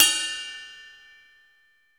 ETT_RIDE01.WAV